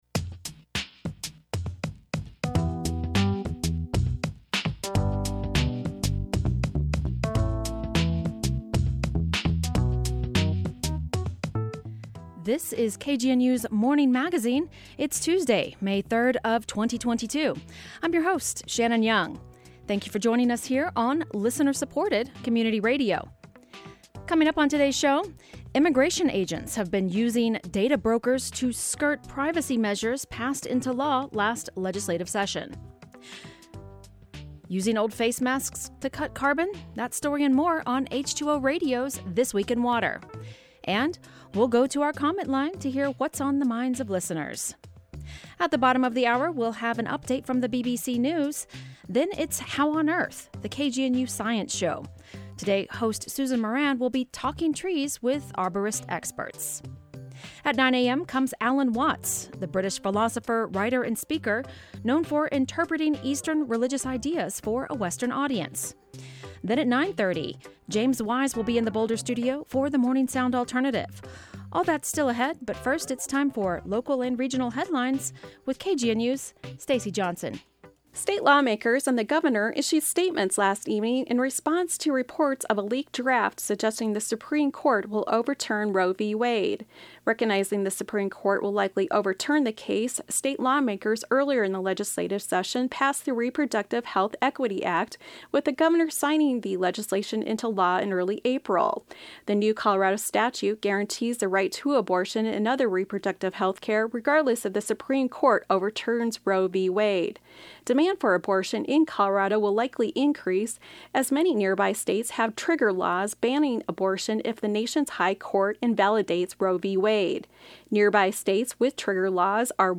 Finally, we’ll go to our comment line to hear what’s on the minds of listeners.